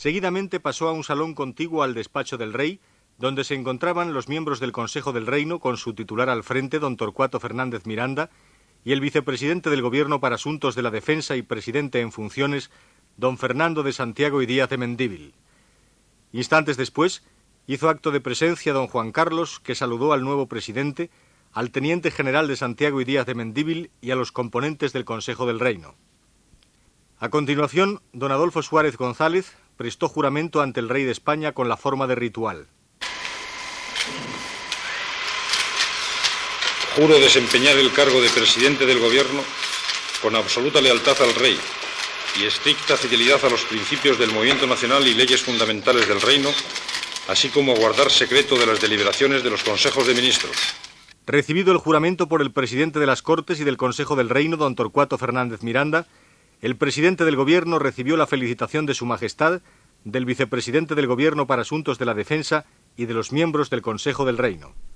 Crònica del jurament i de la presa de possessió d'Adolfo Suárez com a president del govern espanyol
Informatiu